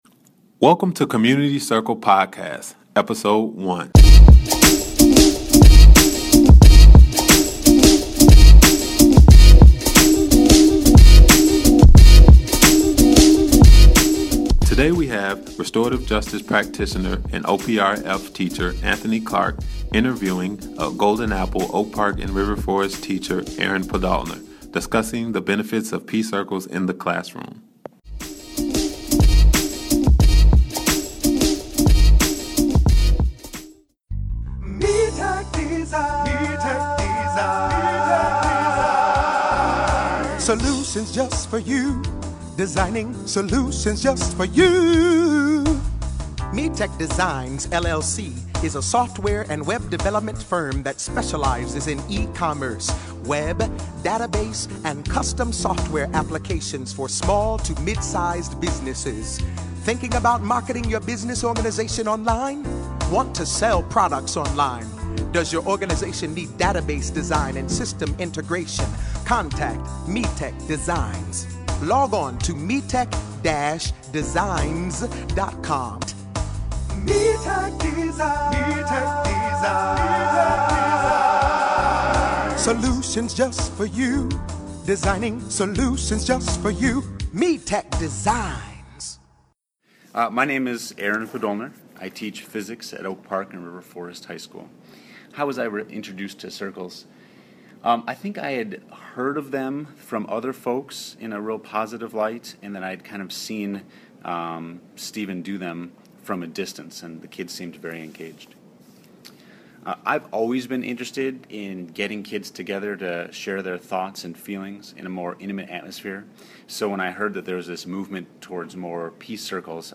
Interview About Peace Circles